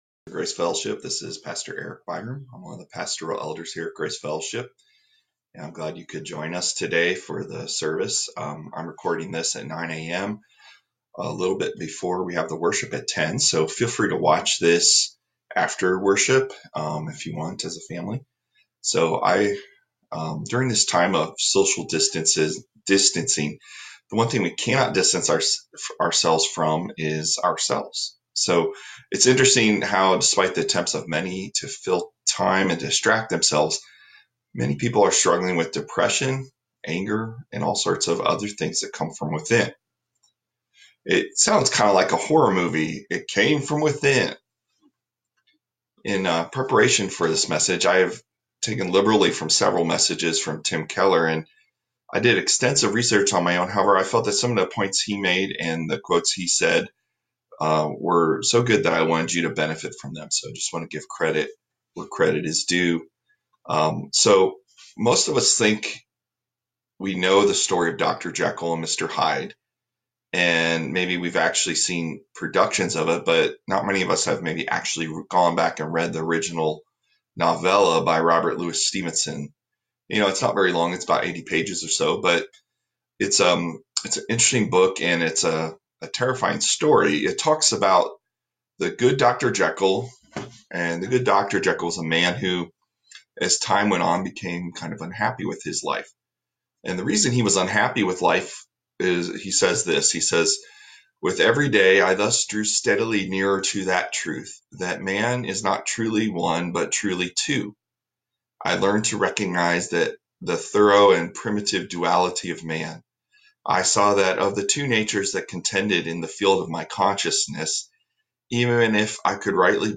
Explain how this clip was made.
Our video message was recorded on Facebook Live.